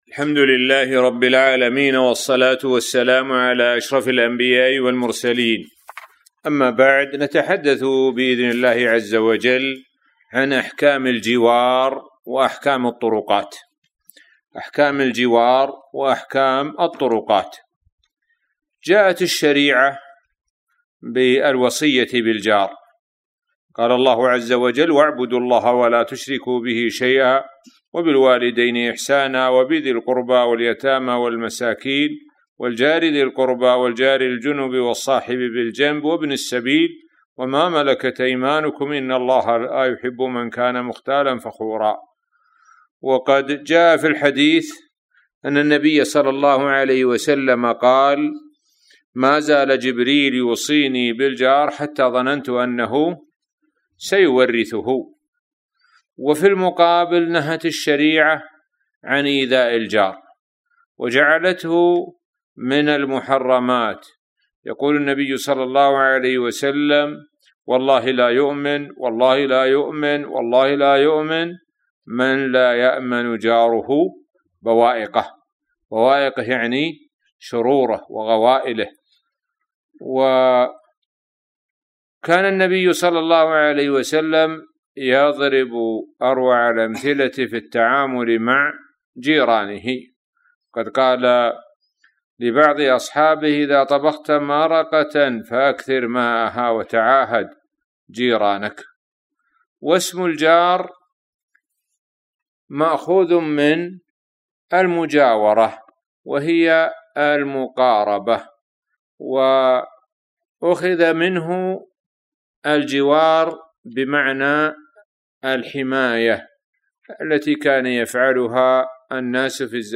الموقع الرسمي لفضيلة الشيخ الدكتور سعد بن ناصر الشثرى | الدرس--101 [أحكام الجوار والطرقات] 1-6-1433